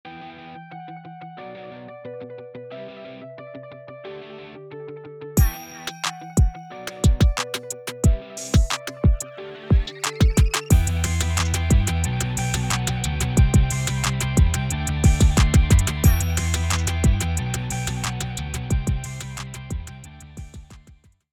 トラップ×ロック